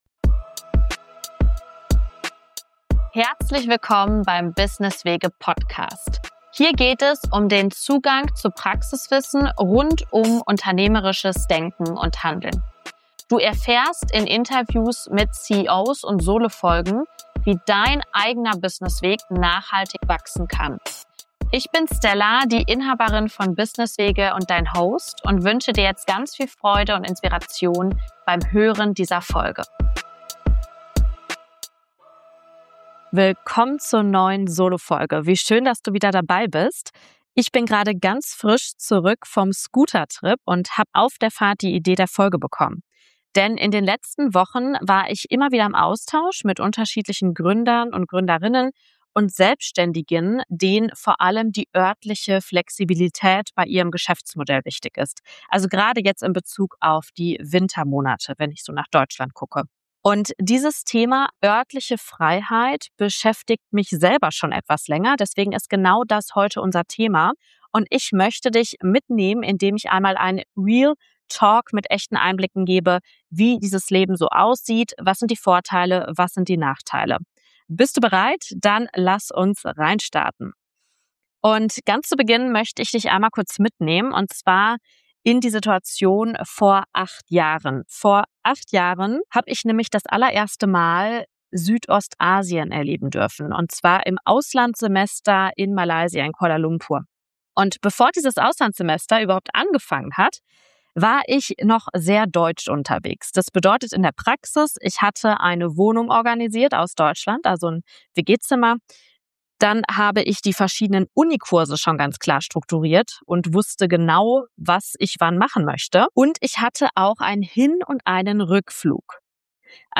In dieser Solo-Folge spreche ich offen über ein Thema, das mich seit vielen Jahren begleitet und das aktuell viele Gründer:innen und Selbstständige umtreibt: örtliche Freiheit im eigenen Geschäftsmodell.